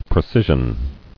[pre·ci·sion]